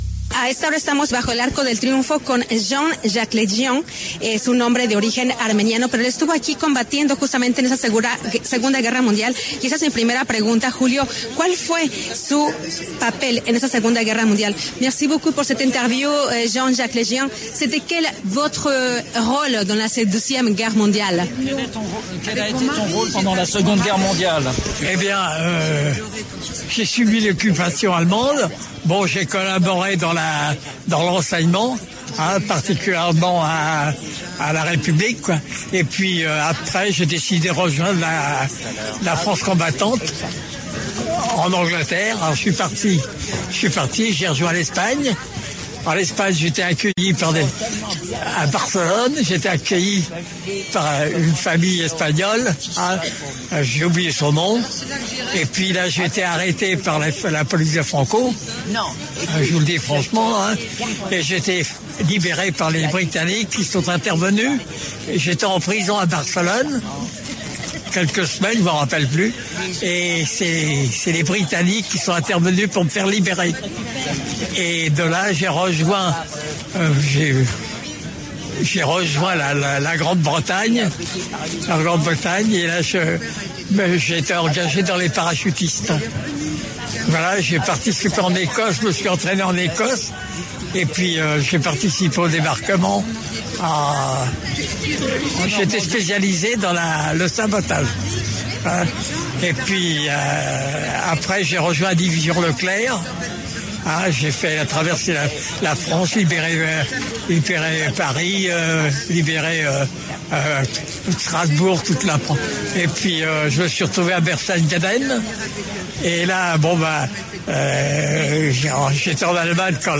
habló con La W desde el Arco del Triunfo en medio de la conmemoración de la victoria de los Aliados ante el régimen nazi.